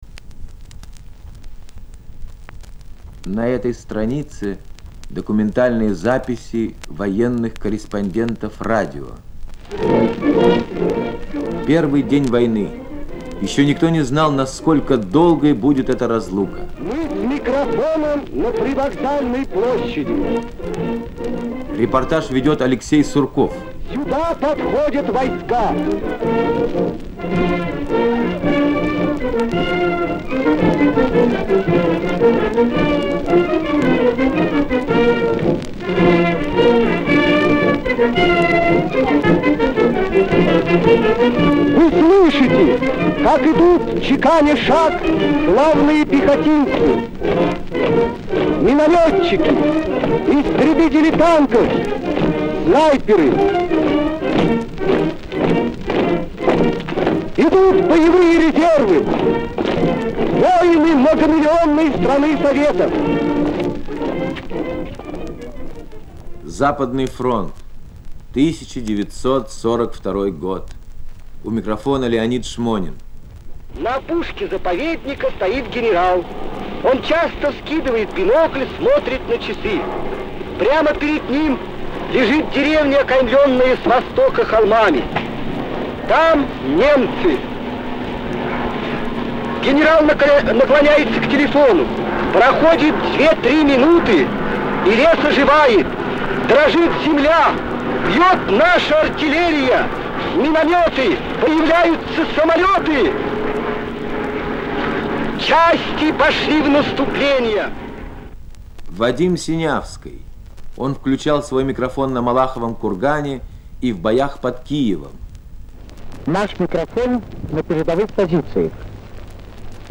Звуковая страница 2 - Шесть минут войны. Документальные записи.